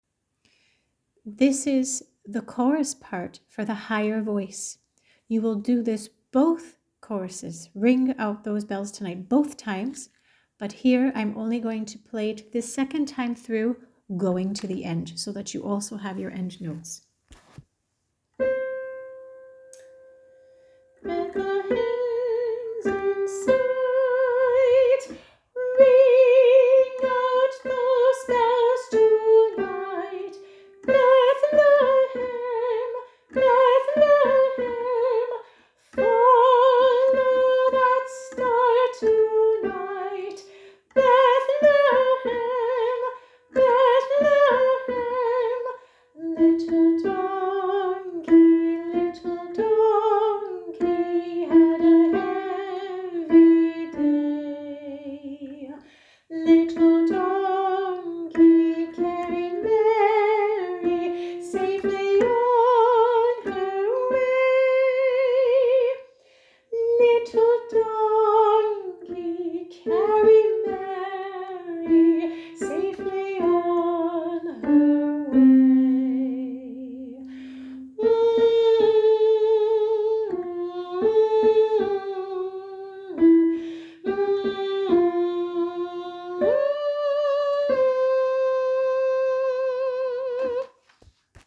Harmony sing along tracks
little-donkey-high-chorus-and-end-notes.m4a